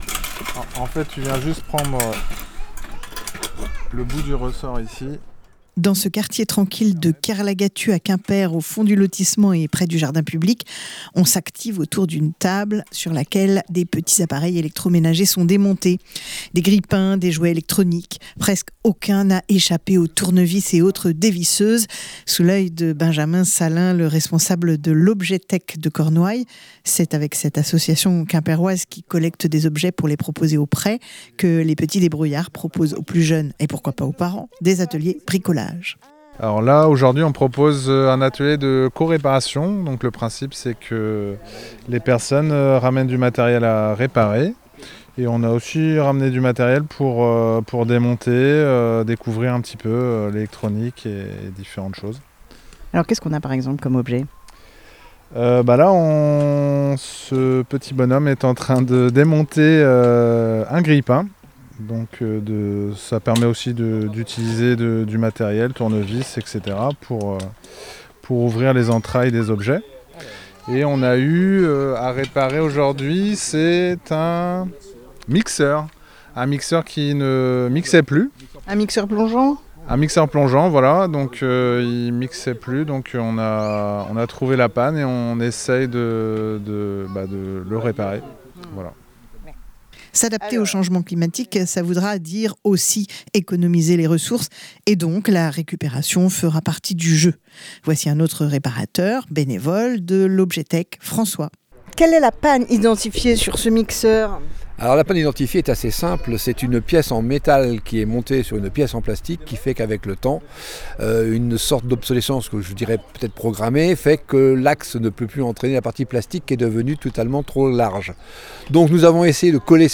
LEMRUB-Petits-debrouillards-adaptation-climat-Quimper-reparation-objets-reportage.mp3